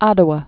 də-wə, ădə-)